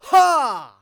xys长声4.wav 0:00.00 0:00.83 xys长声4.wav WAV · 72 KB · 單聲道 (1ch) 下载文件 本站所有音效均采用 CC0 授权 ，可免费用于商业与个人项目，无需署名。
人声采集素材